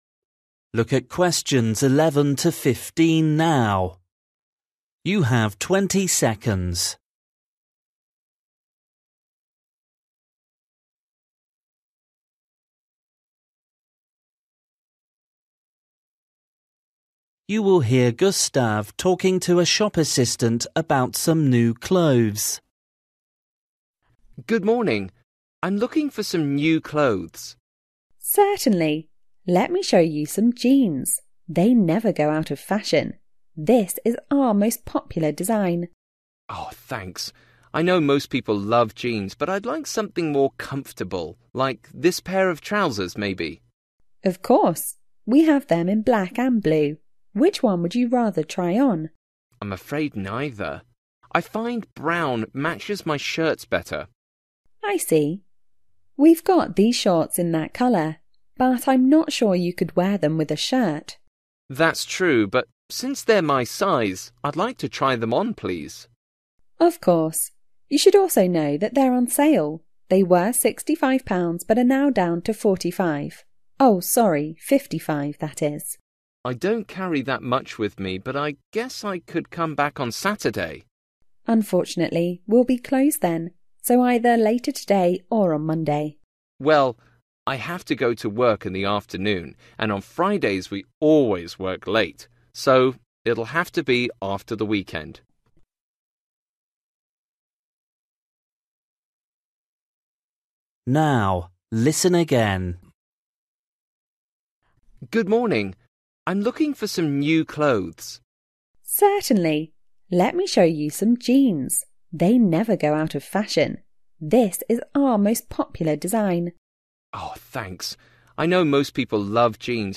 You will hear Gustav talking to a shop assistant about some new clothes.